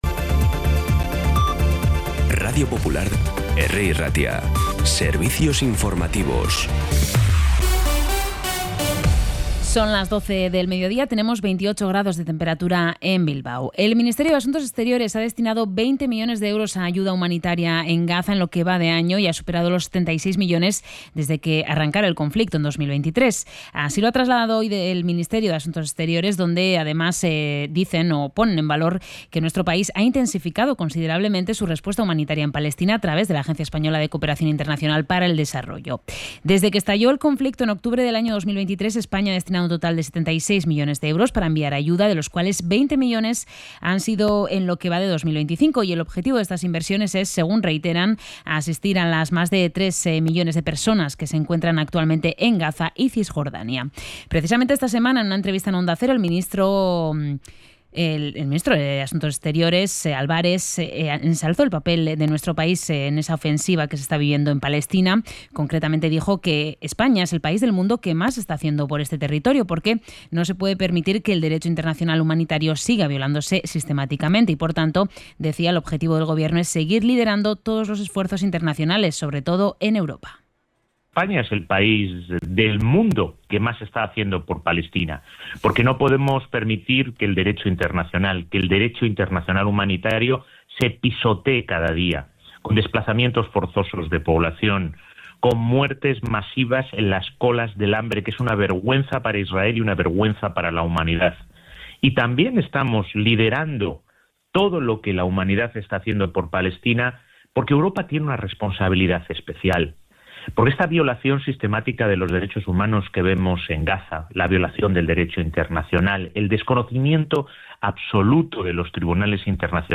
Información y actualidad desde las 12 h de la mañana